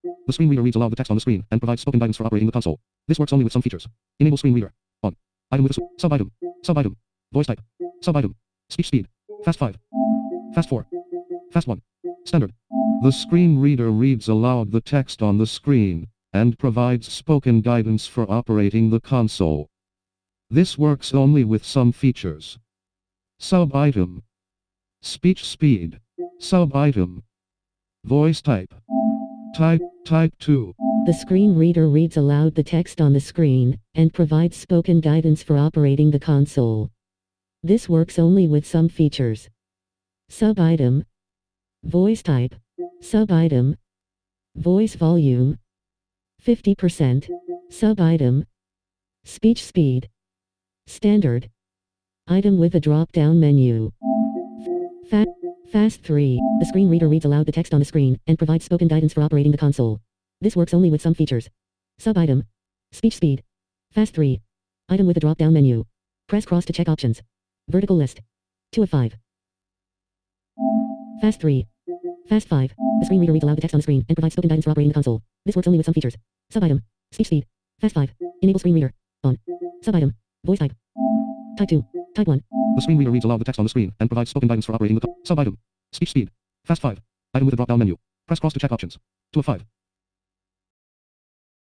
Here's how the US playstation 5 tts sounds like